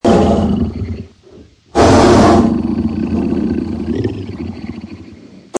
Angry Tiger Fantasy Bouton sonore
The Angry Tiger Fantasy sound button is a popular audio clip perfect for your soundboard, content creation, and entertainment.